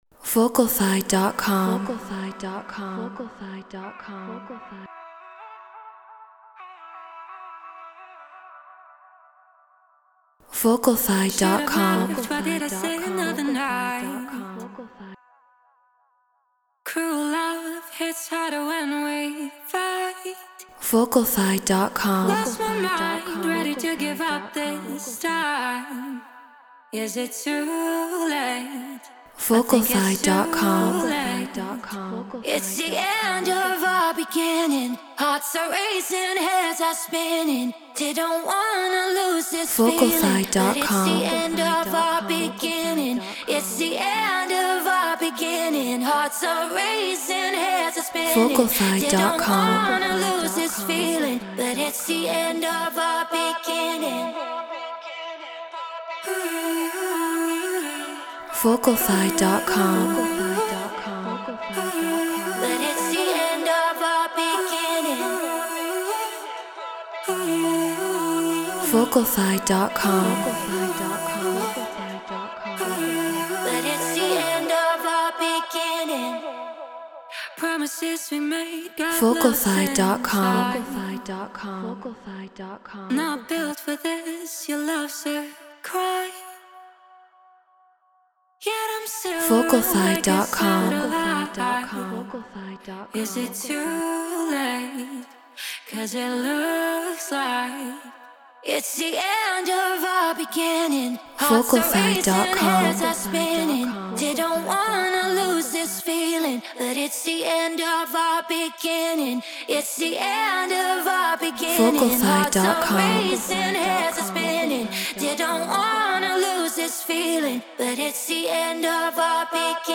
Drum & Bass 174 BPM Dmaj
Treated Room